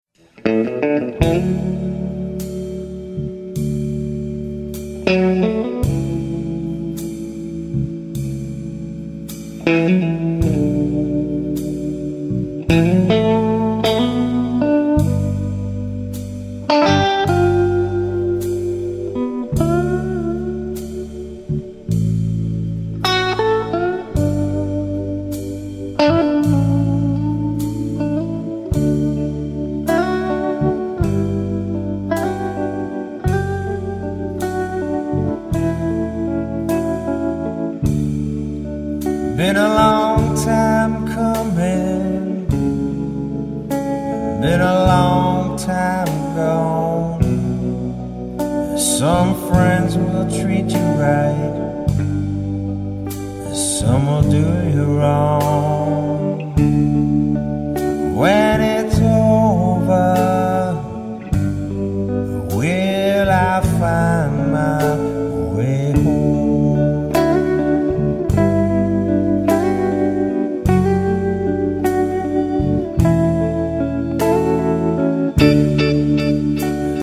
(Electric, Acoustic, Resonator, Octave 12 String & Baritone)
Mixed at SERPENTINE STUDIO Central Valley, NY